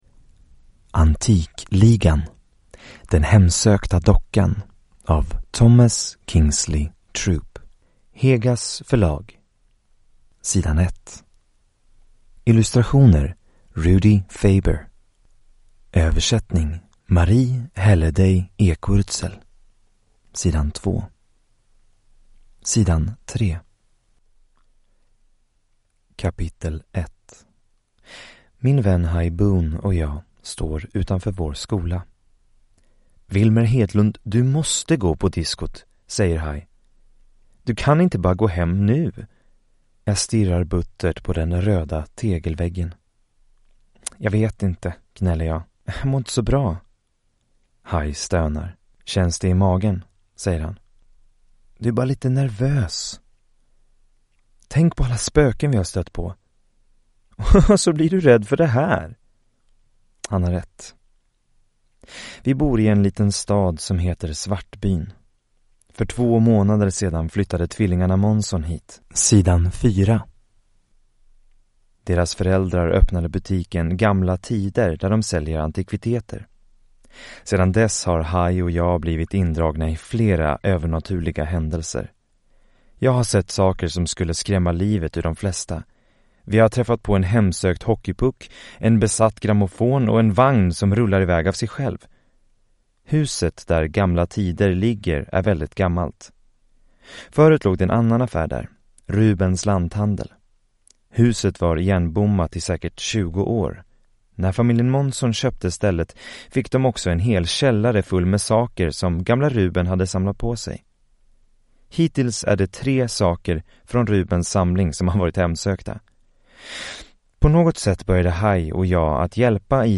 Den hemsökta dockan (ljudbok) av Thomas Kingsley Troupe | Bokon